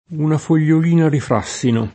una fol’l’ol&na di fr#SSino] (Cicognani) — sim. i top. Frassini (Tosc.), Frassino, e il cogn. Da Frassini